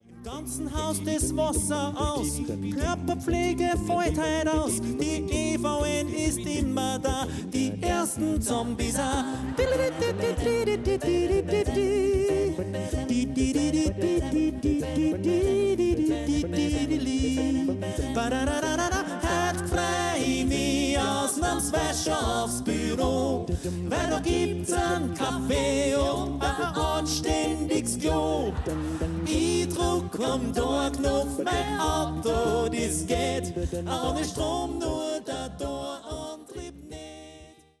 a cappella-Konzertpackage